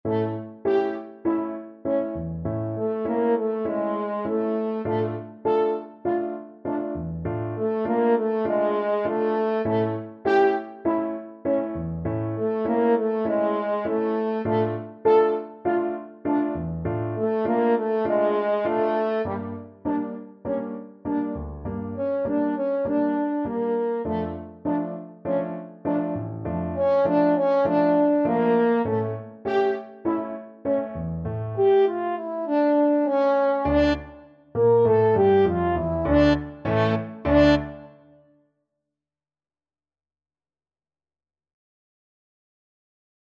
Tempo di Tango
4/4 (View more 4/4 Music)